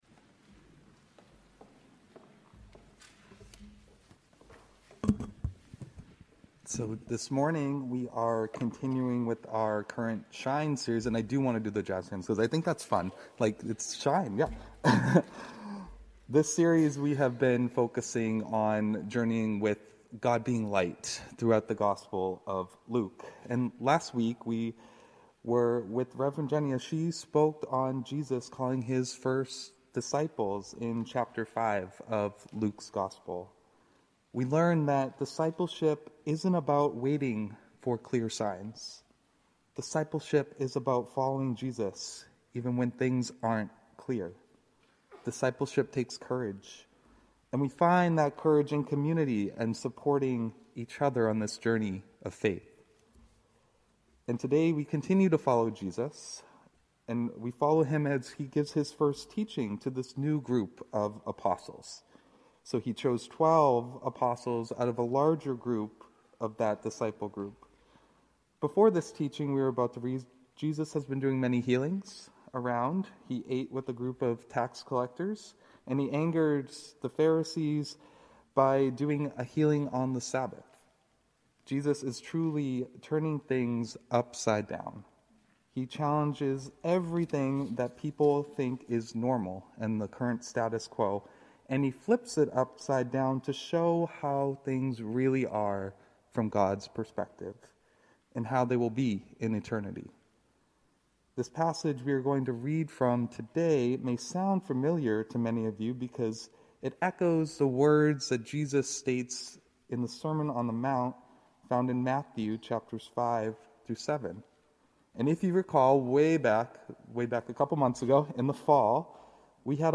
A message from the series "Shine."